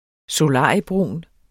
Udtale [ soˈlɑˀiəˌbʁuˀn ]